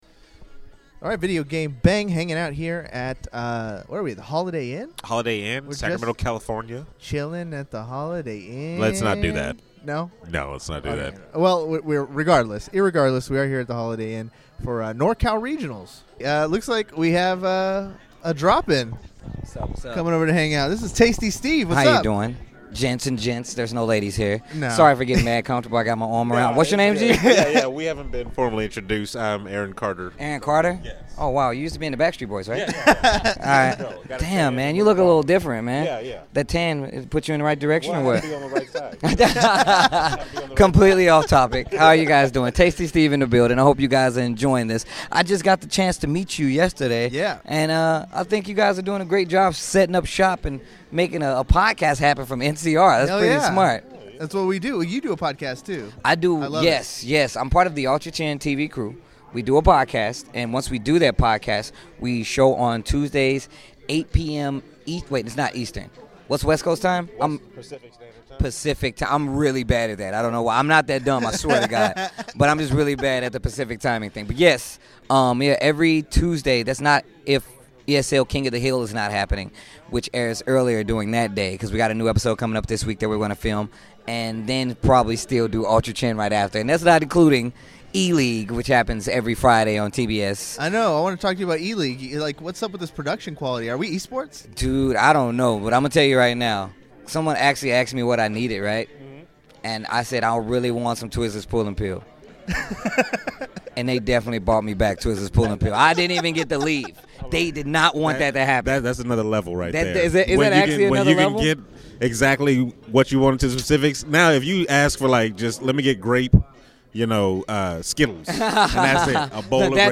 podcast from NCR 2017.